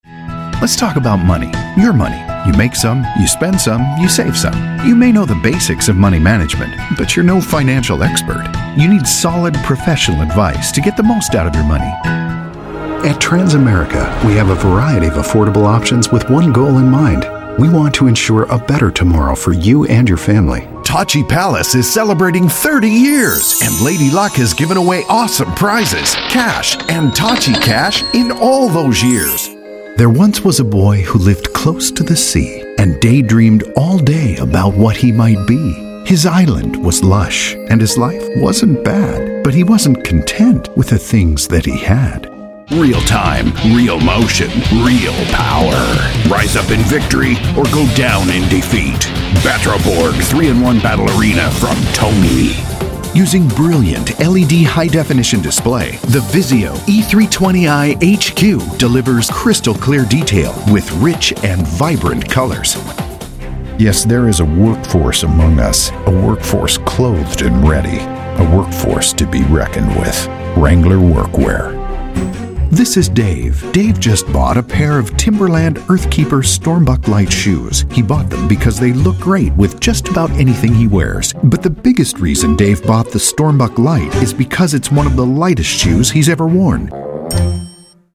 Warm, authentic, trustworthy, experienced English male voice.
Sprechprobe: Werbung (Muttersprache):